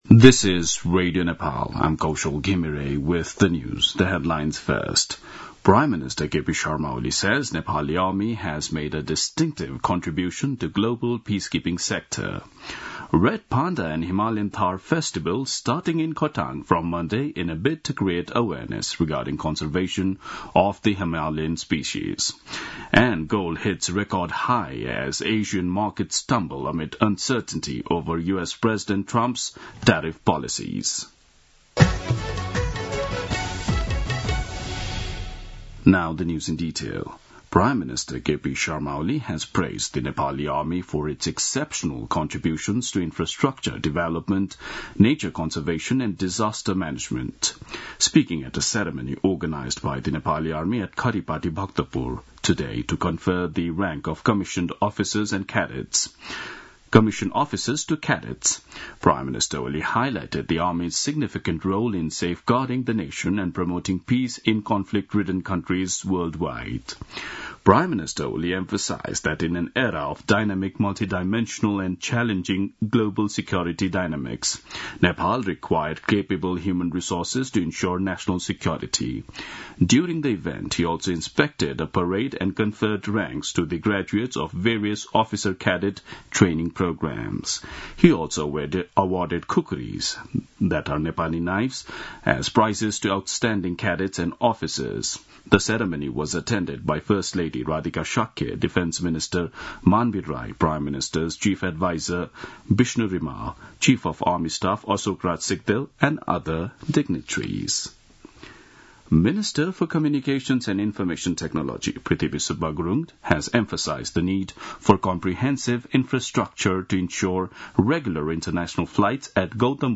An online outlet of Nepal's national radio broadcaster
दिउँसो २ बजेको अङ्ग्रेजी समाचार : २९ चैत , २०८१
2-pm-news-1-3.mp3